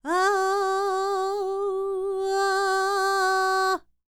QAWALLI 09.wav